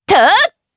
Lewsia_B-Vox_Attack4_jp.wav